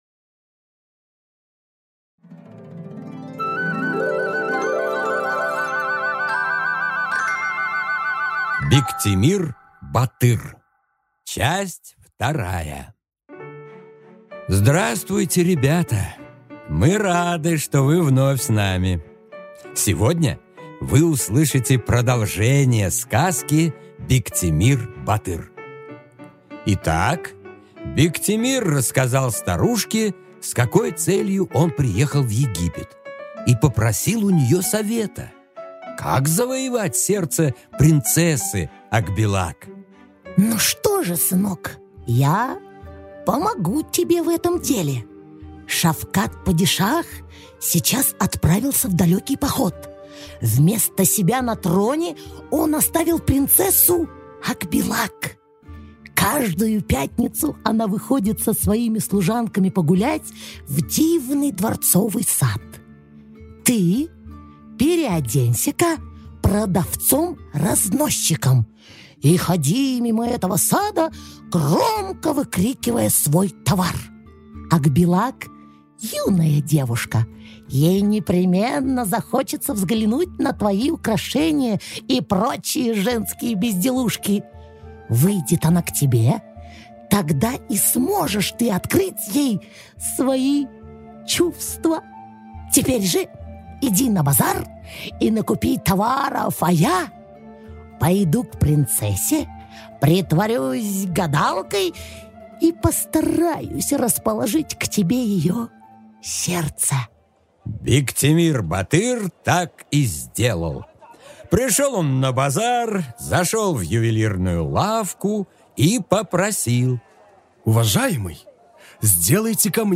Аудиокнига Бектемир-батыр. 2 часть | Библиотека аудиокниг